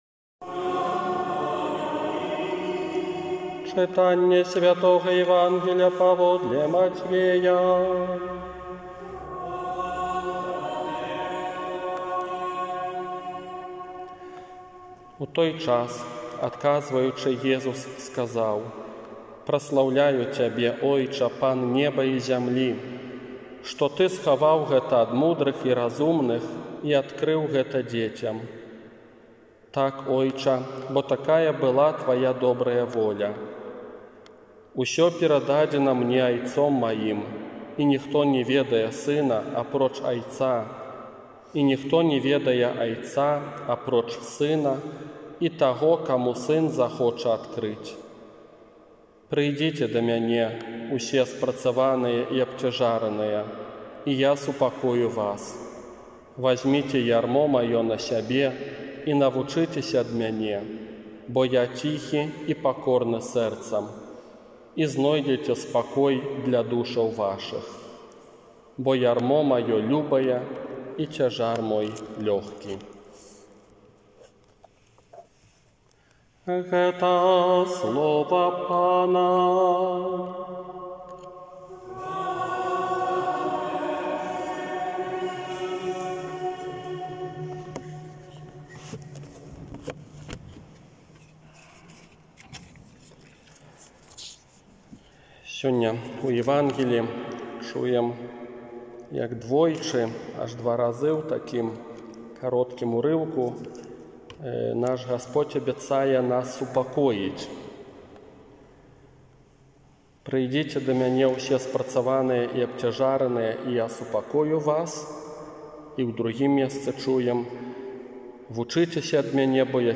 ОРША - ПАРАФІЯ СВЯТОГА ЯЗЭПА
Казанне на шаснаццатую звычайную нядзелю 5 ліпеня 2020 года
Пакора_i_суцяшэнне_казанне.m4a